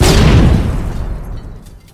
CosmicRageSounds / ogg / ships / combat / indirect1.ogg